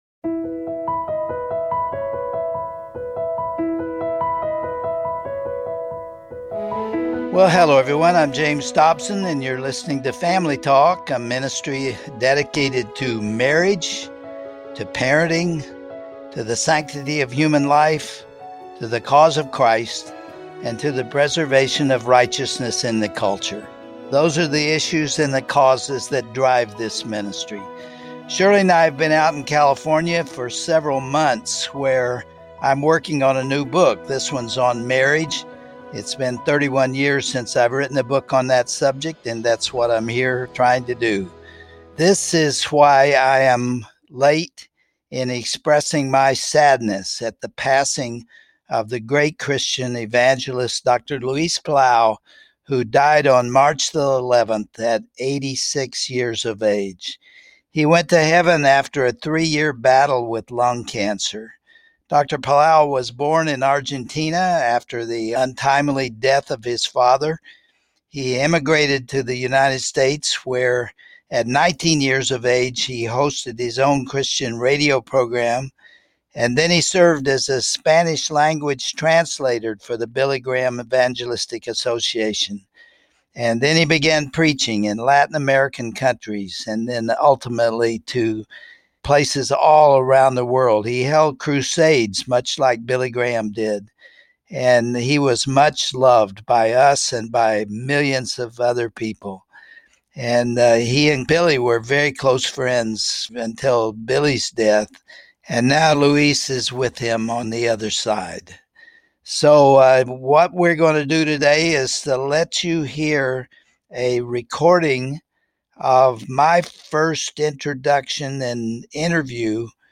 On today's broadcast, Dr. James Dobson opens with a special tribute to the life work and impact of Dr. Palau, and then plays a classic interview they had previously recorded together.